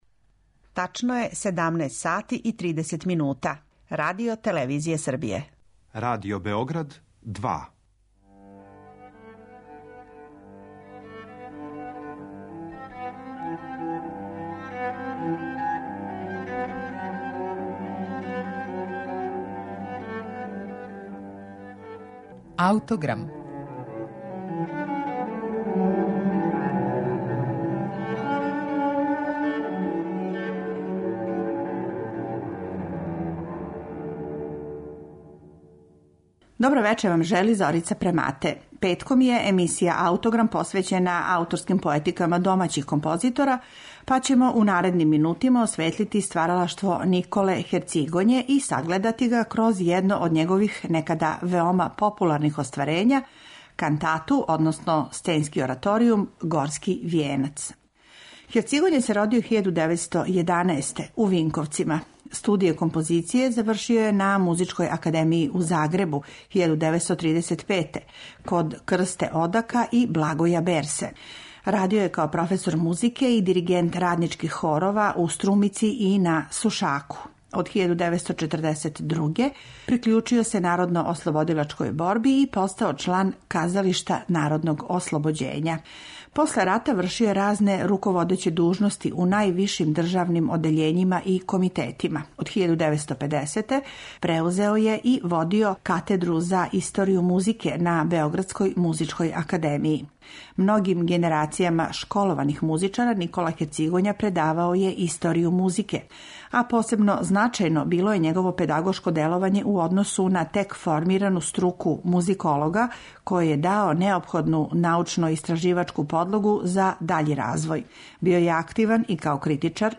архивски снимак сценског ораторијума
бас
тенор